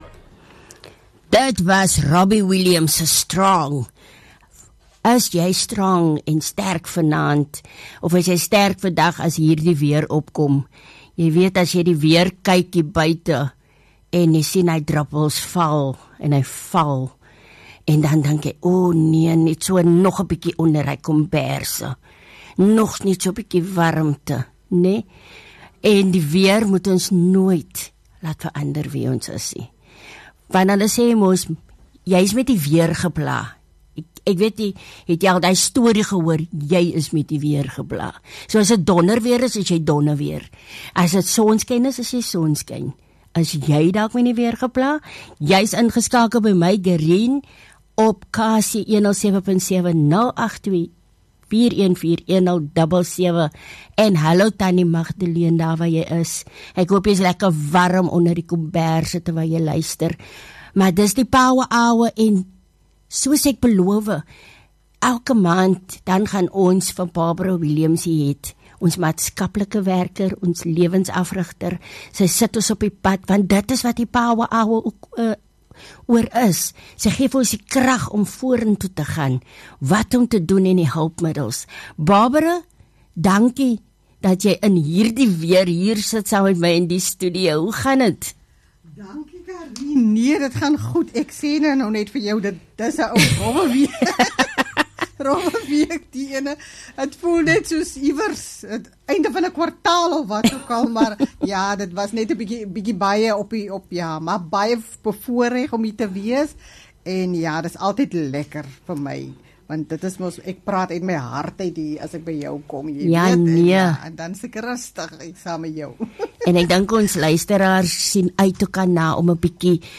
Onderhoud met Maatskaplike werker en Lewensafrigter